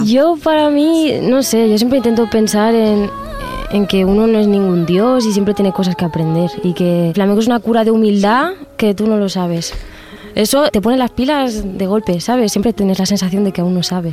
Entrevista a la cantant Rosalía (Rosalia Vila) que presenta el disc 'Los Ángeles'.
Resposta de Rosalía a una de les preguntes.